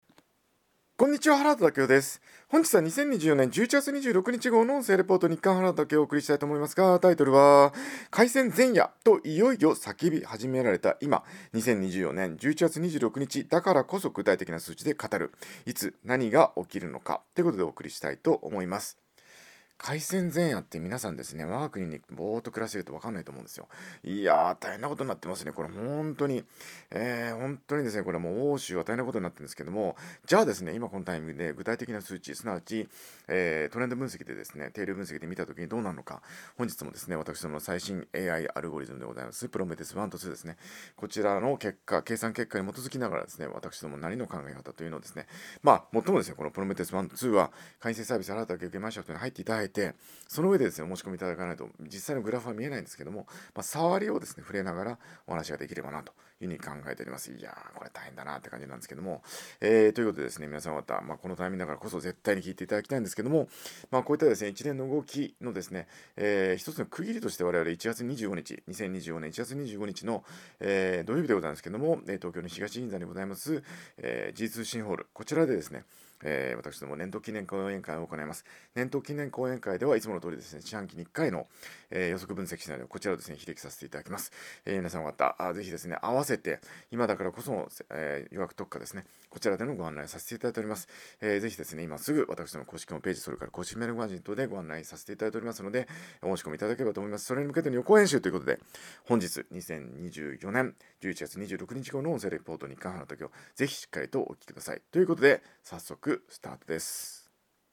音声レポート